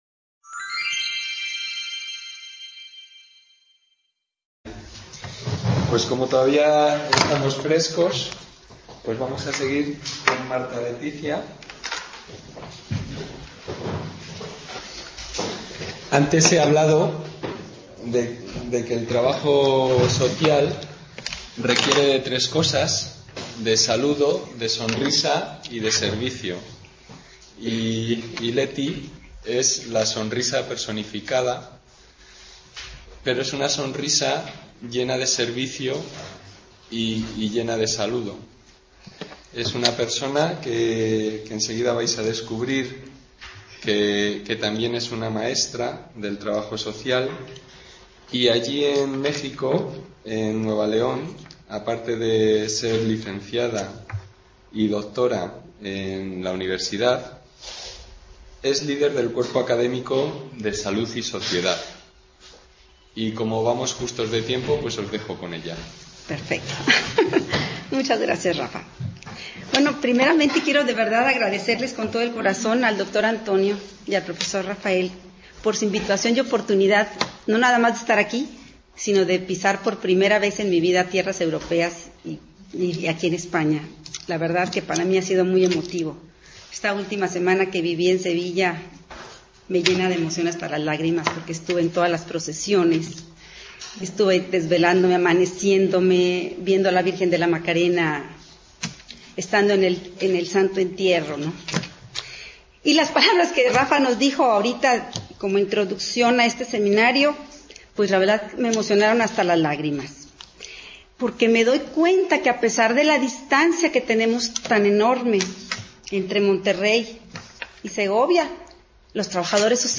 Seminario de investigación sobre Trabajo Social, Estado del Bienestar y Metodologías de Investigación Social